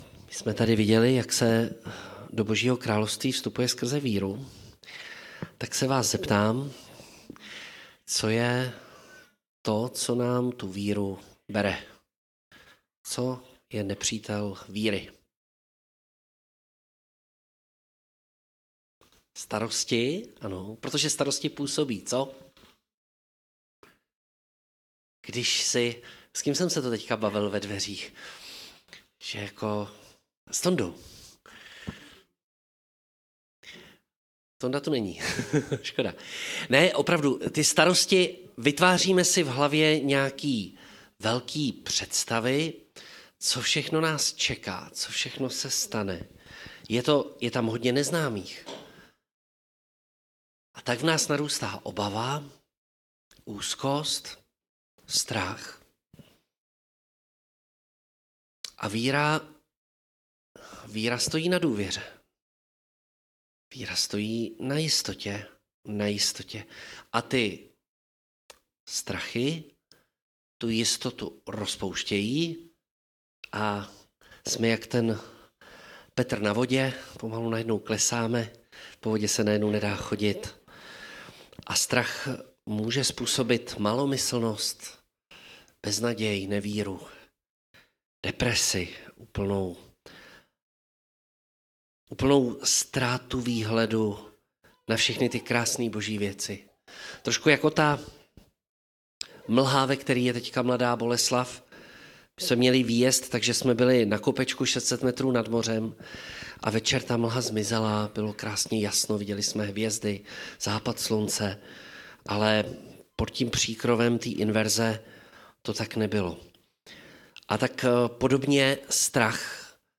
Zveme vás k poslechu kázání z nedělního shromáždění Jednoty bratrské v Mladé Boleslavi: